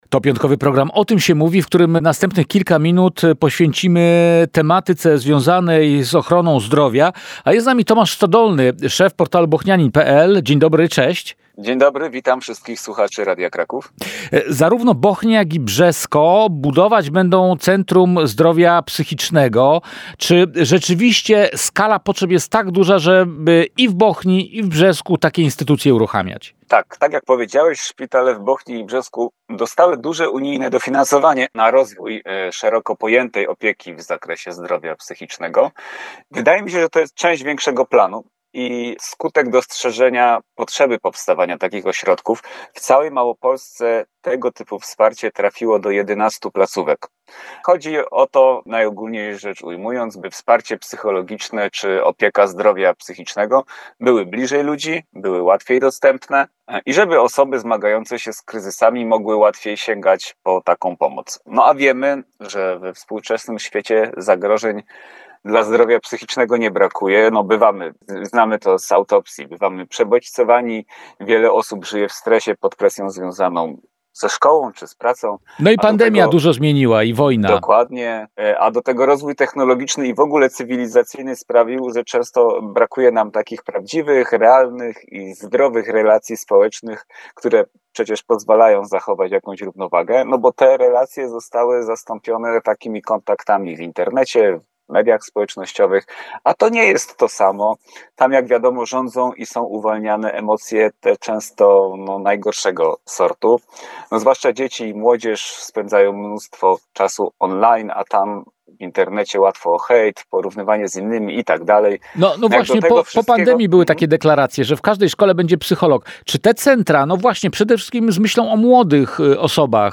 Po co Bochnia i Brzesko budują centra zdrowia psychicznego? Dlaczego zlikwidowano porodówkę w Brzesku i czy to przez taki oddział w Bochni? – na te pytania padły odpowiedzi w piątkowej audycji Radia Kraków
Całej rozmowy można posłuchać na stronach Radia Kraków – TUTAJ